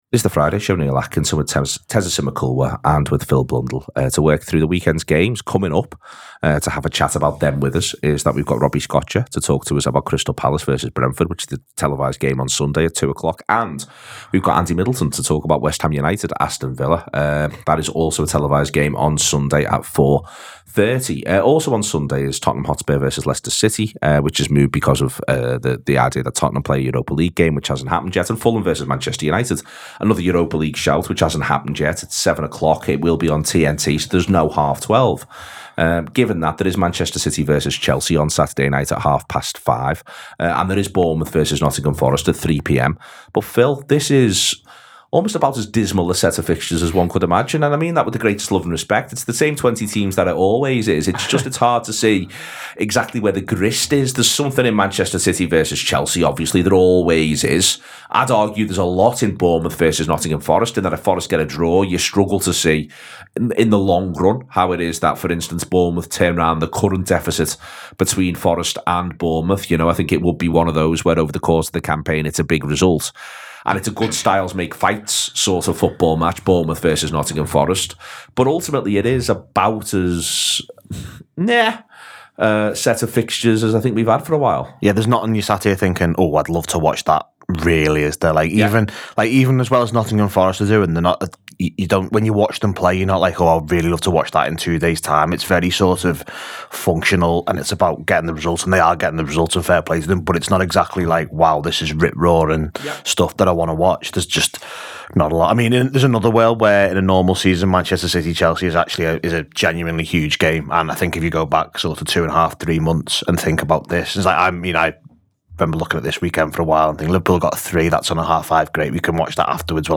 The Anfield Wrap’s weekend preview show with all eyes on a must win game for Spurs with manager Ange Postecoglou under immense pressure.